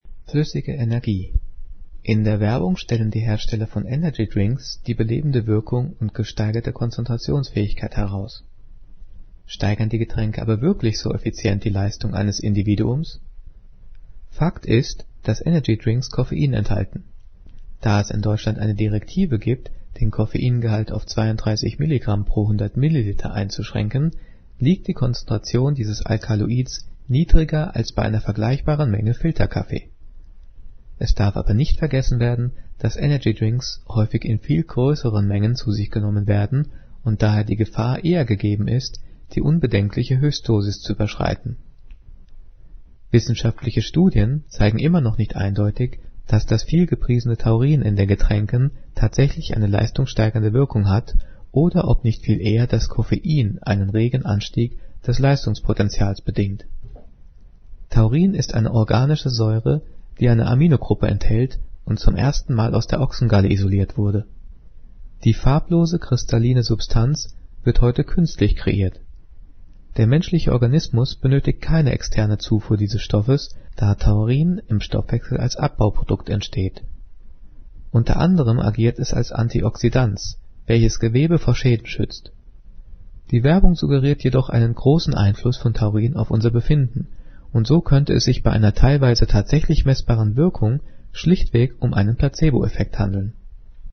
Diktat: "Flüssige Energie" - 7./8. Klasse - Fremdwörter
Gelesen:
gelesen-fluessige-energie.mp3